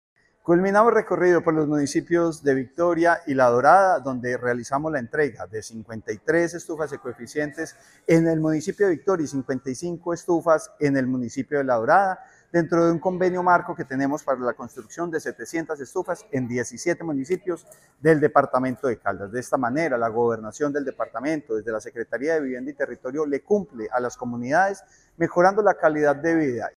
Francisco Javier Vélez Quiroga, secretario de Vivienda de Caldas.
Francisco-Javier-Velez-Quiroga-Secretario-de-vivienda-de-Caldas.mp3